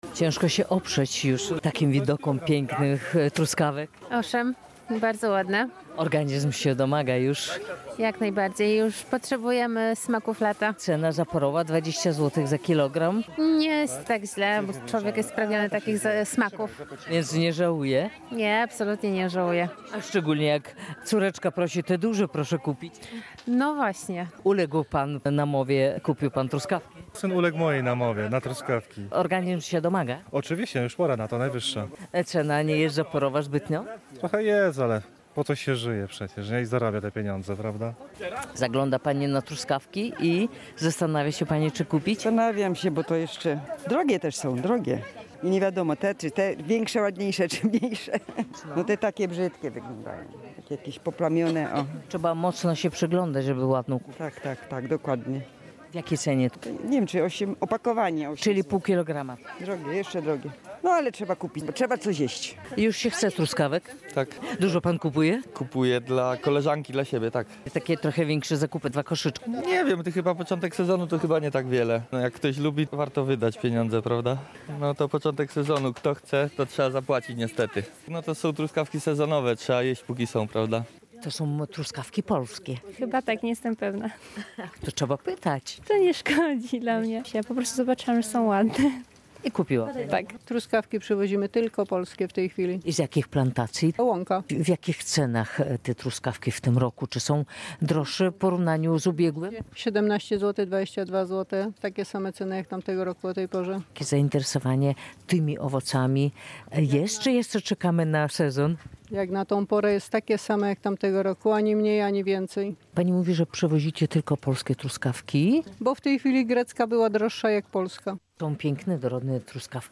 Zdaniem pytanych przez nas kupujących, na te owoce nie warto żałować pieniędzy, bo szybko się kończą.